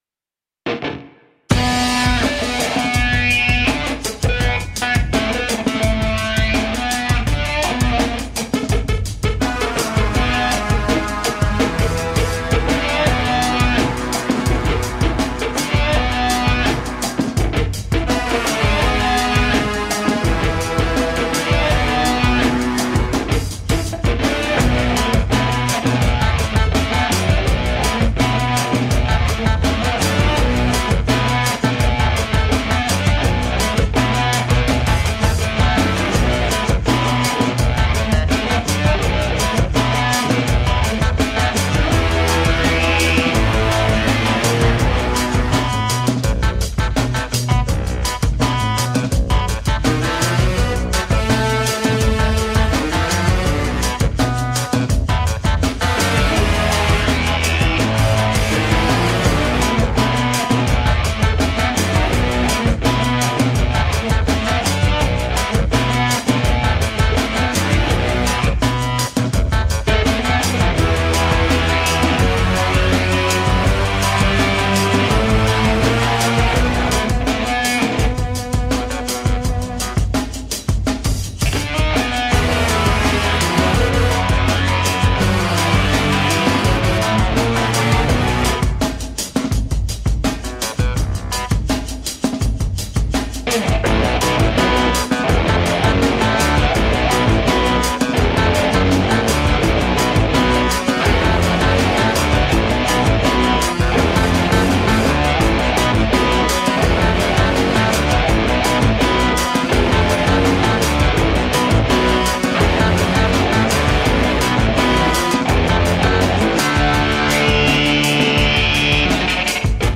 Tagged as: Alt Rock, Rock, Classic rock, Instrumental